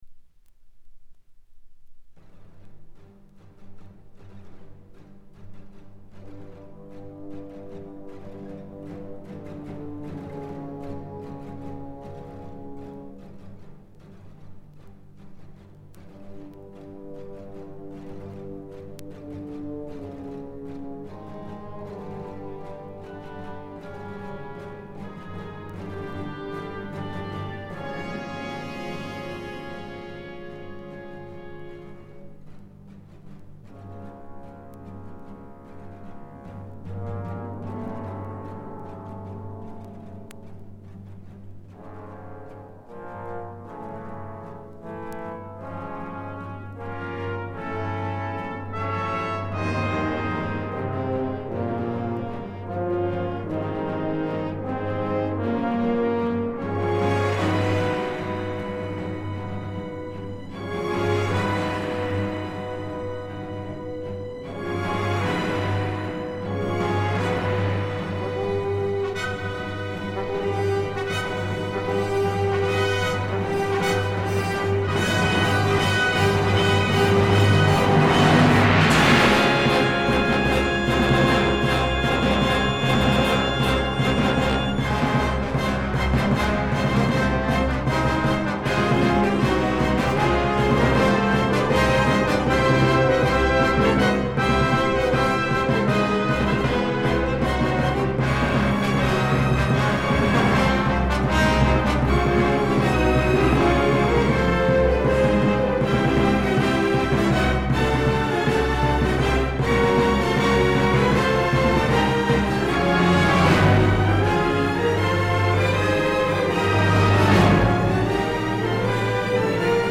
比較試聴した時の楽曲は全てDSD 5.6M で録音しました。
クラシック、オーケストラ